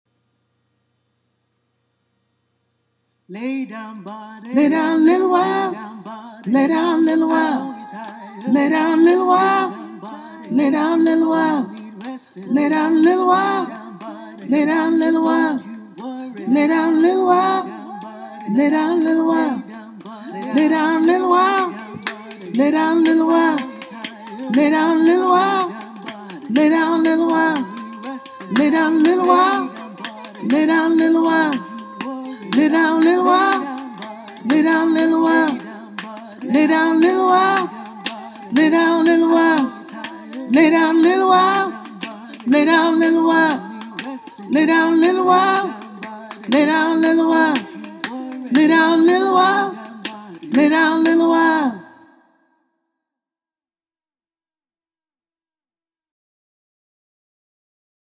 Lay-Down-Body-Sop.mp3